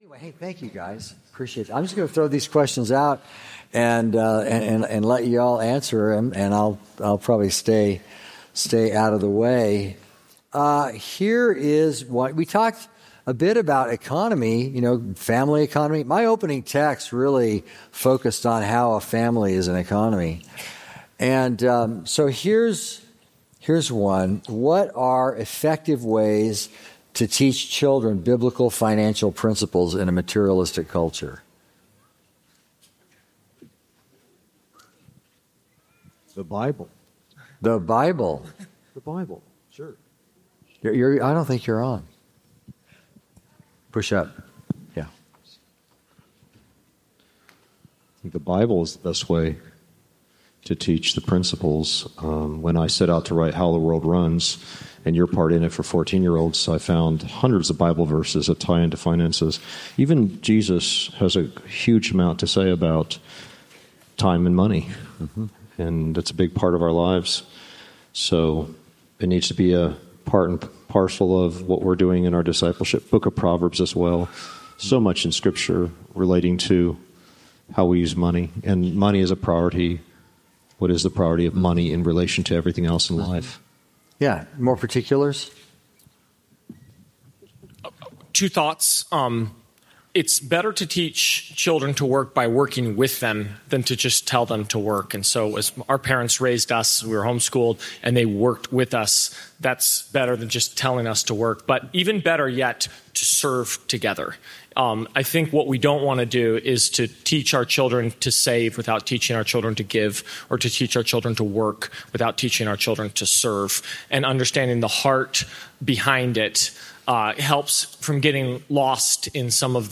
Q & A Panel Session